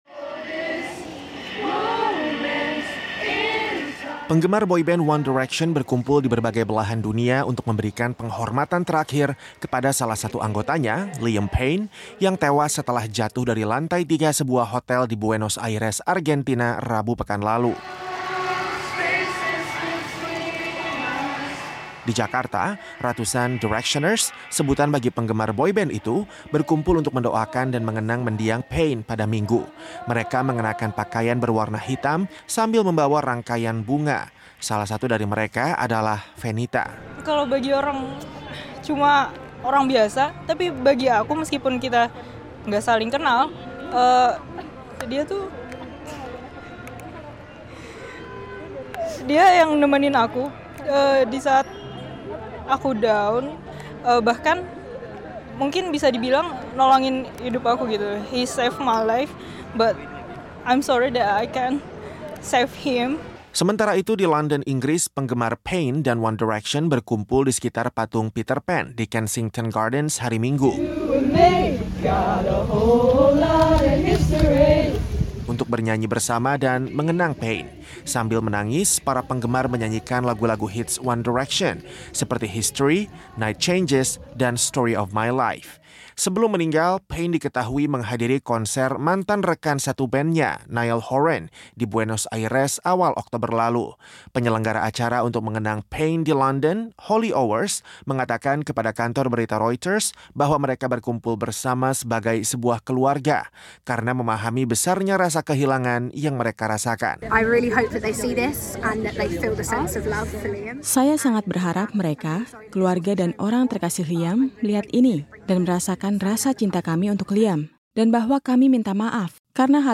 Alunan lagu One Direction terdengar di berbagai kota di seluruh dunia ketika para penggemar bernyanyi untuk memberikan penghormatan terakhir kepada mendiang Liam Payne, salah satu mantan anggota boyband tersebut, yang tewas setelah jatuh dari hotelnya pekan lalu di Argentina.